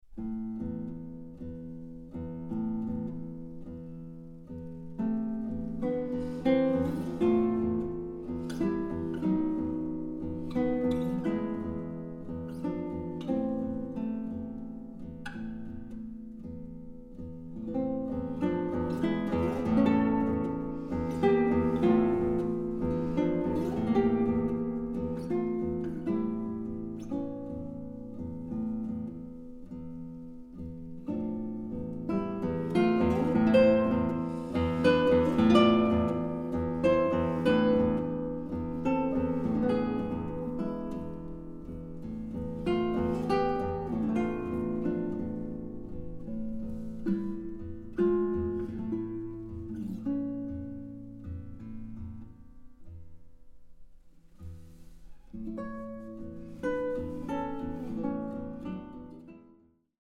Eine musikalische Collage kanonischer Gitarrenwerke
Gitarre